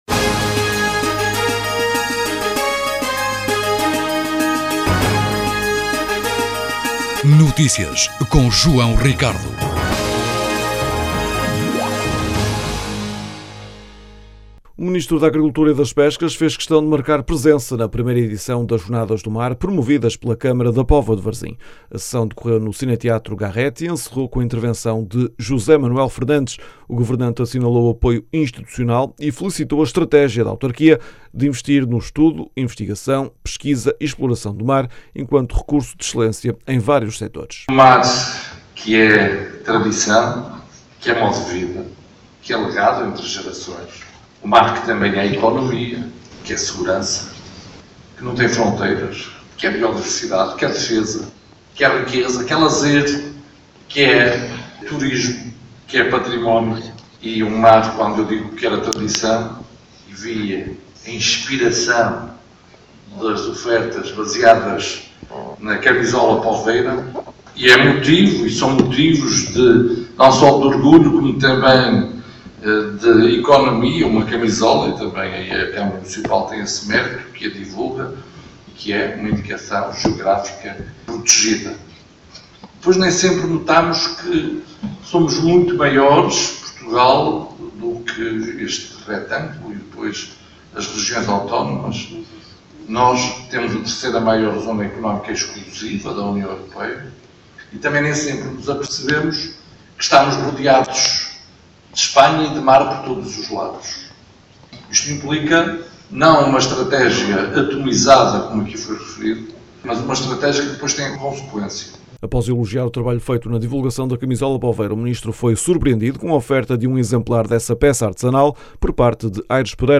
A sessão decorreu no Cine-Teatro Garrett e encerrou com a intervenção de José Manuel Fernandes. O governante assinalou o apoio institucional e felicitou a estratégia da autarquia de investir no estudo, investigação, pesquisa e exploração do mar, enquanto recurso de excelência, em vários setores.
As declarações podem ser ouvidas na edição local.